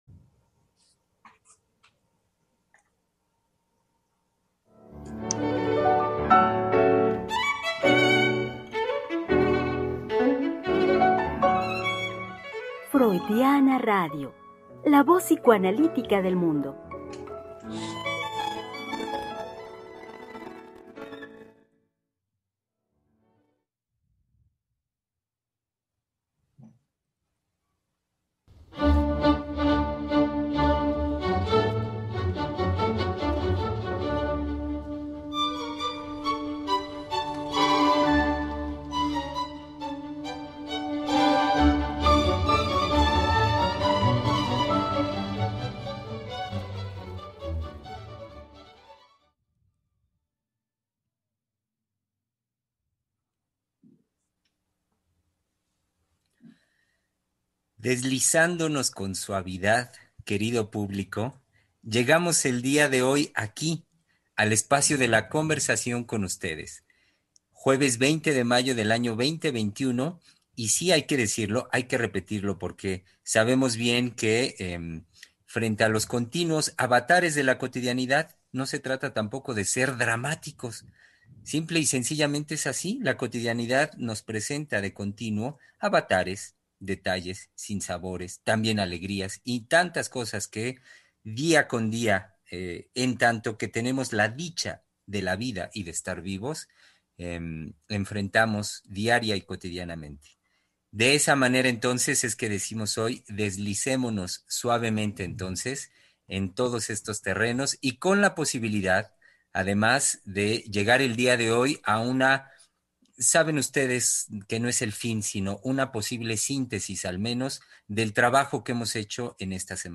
Programa transmitido el 20 de mayo del 2021.